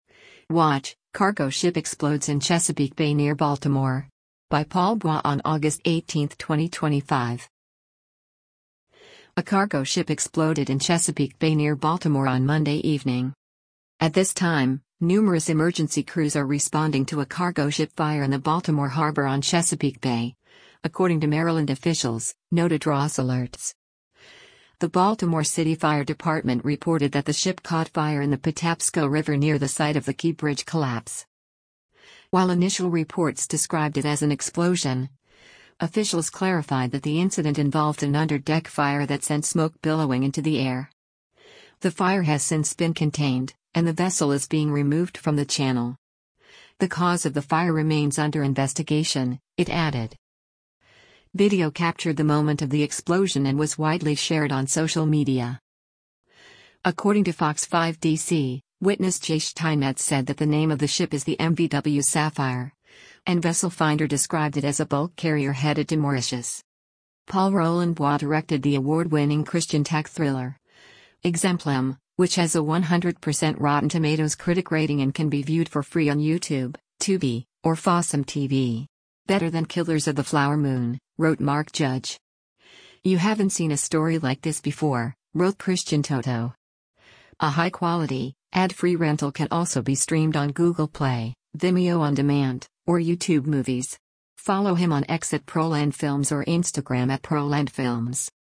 Video captured the moment of the explosion and was widely shared on social media.